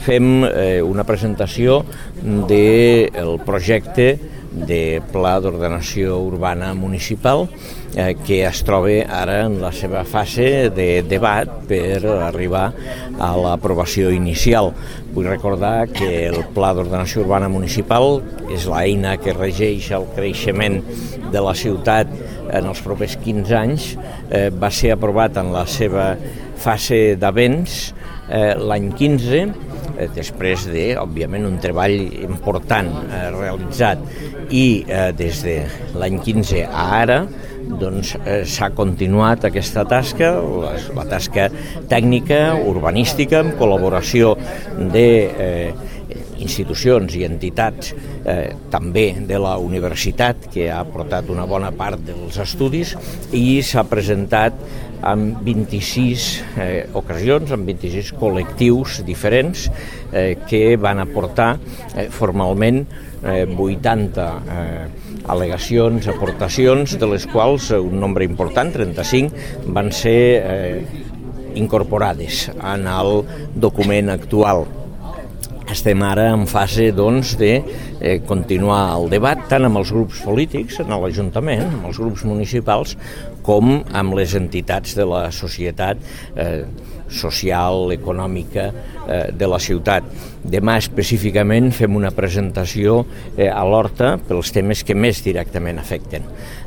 Talls de veu d’Àngel Ros
tall-de-veu-dangel-ros-sobre-el-proces-del-laboracio-del-nou-poum